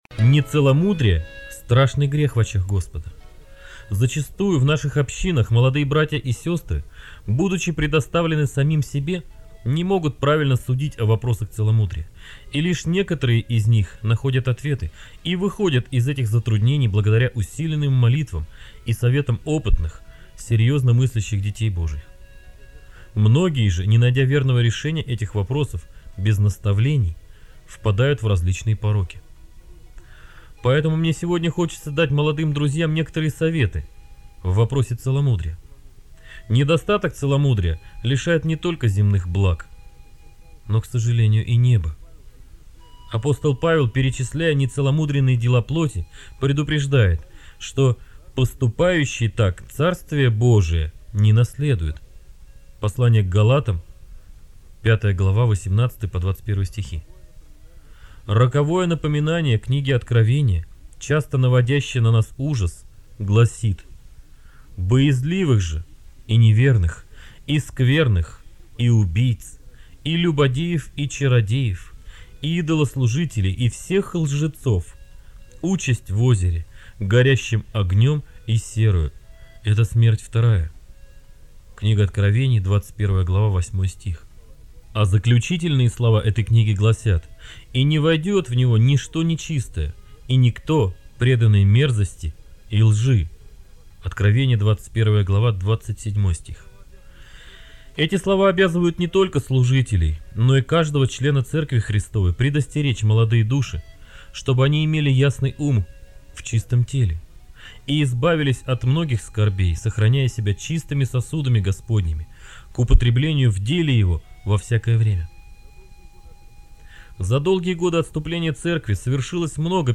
Беседы